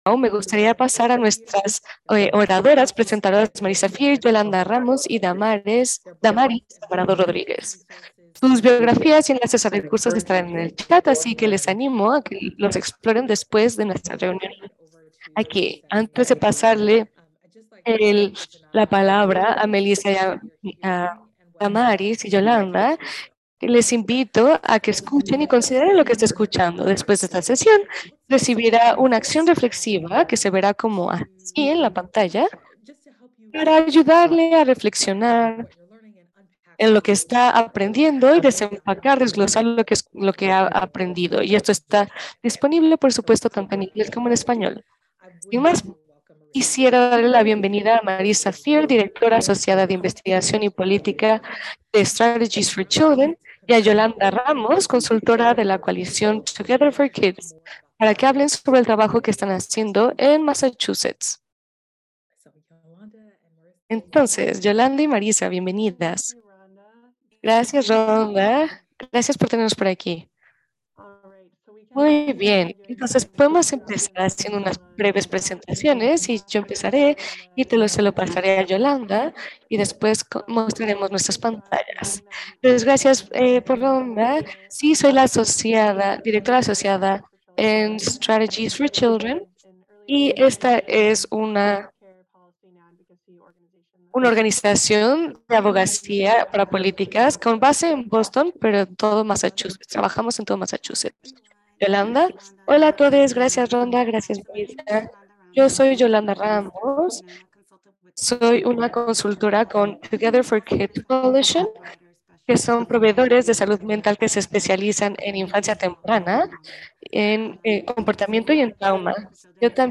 Webinar Archive: Creating Pathways for Community Leadership